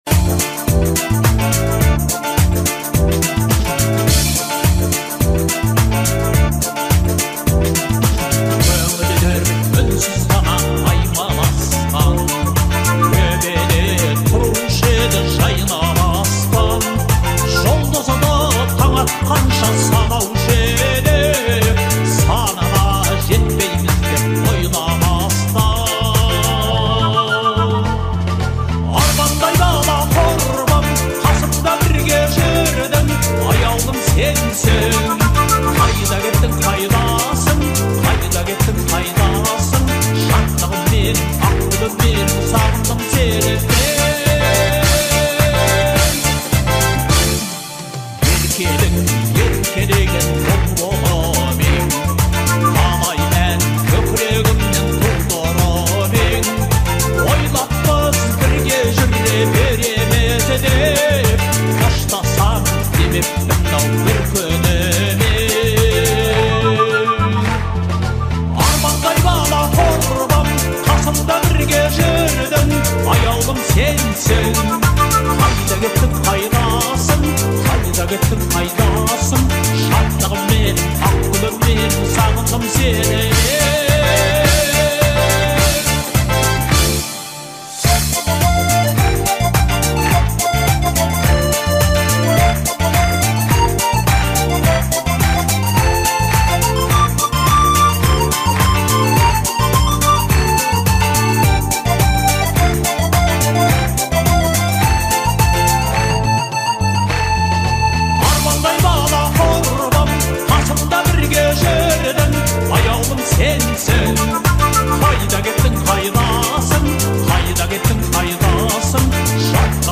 это трогательная песня в жанре казахского фольклора